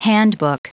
Prononciation du mot handbook en anglais (fichier audio)